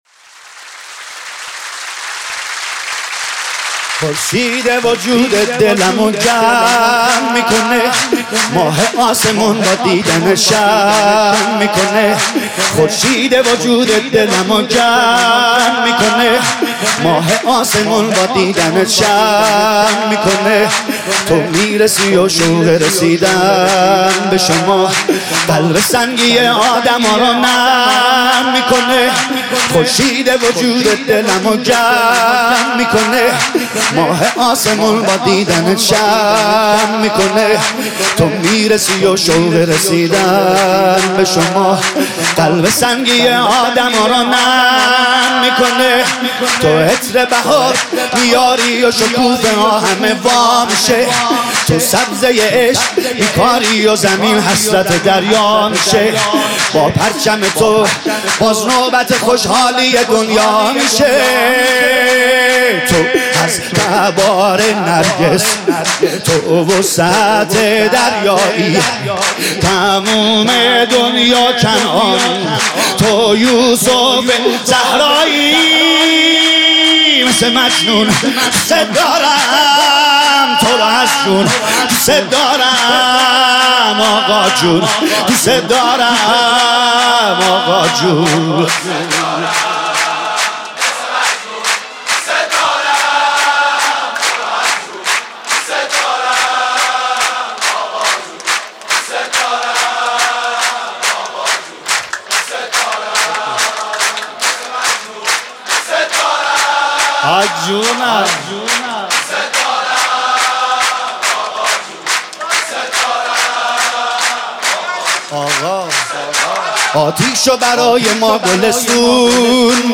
مولودی شاد